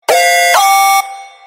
Categoria Allarmi